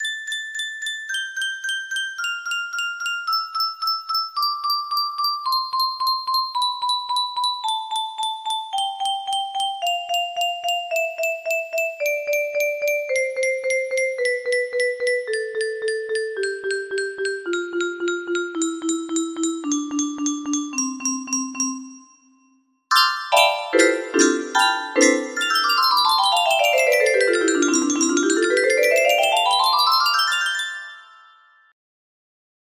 Test music box melody